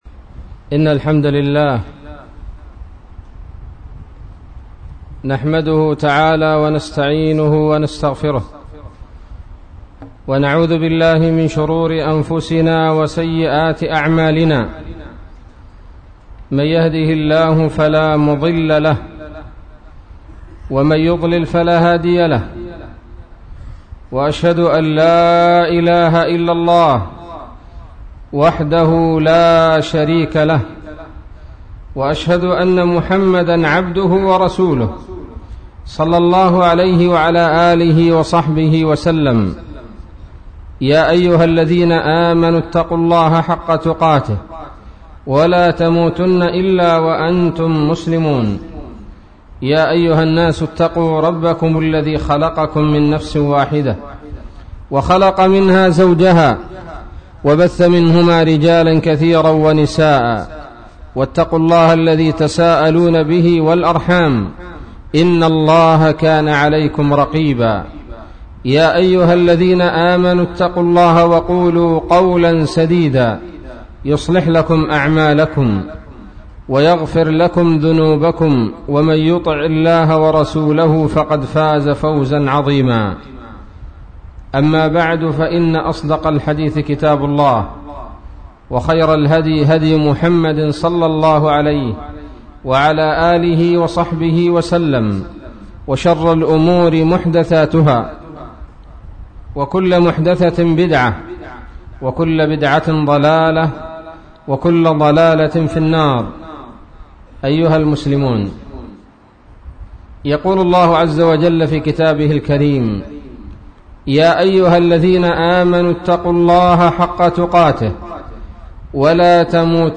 محاضرة بعنوان : ((النميمة وعواقبها)) 14 ربيع أول 1437 هـ